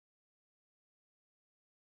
1 channel
snd_2714_silence.wav